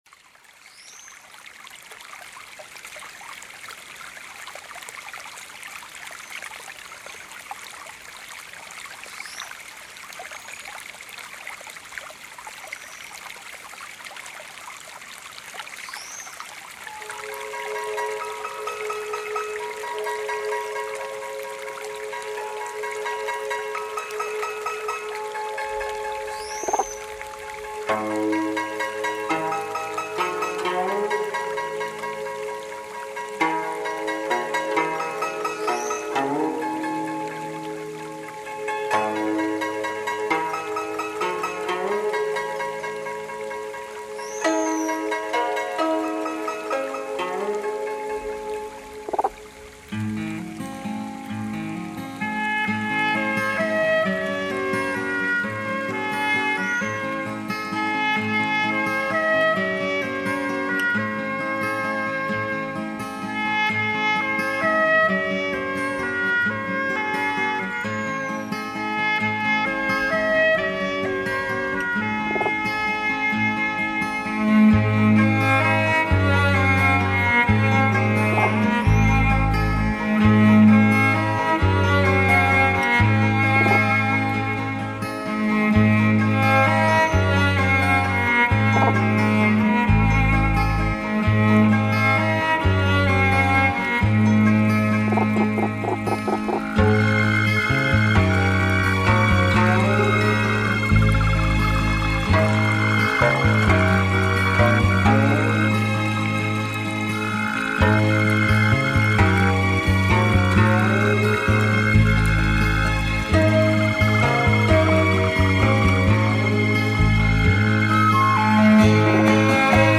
精選15首100％大自然音樂
仿如来到了森林，
虫鸣~
鸟啼~
小河流水~